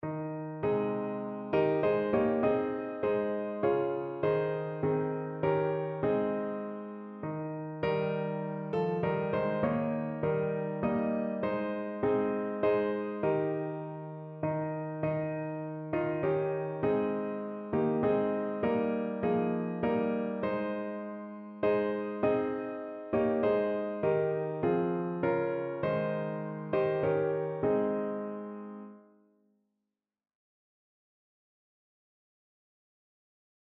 Evangeliumslieder Hingabe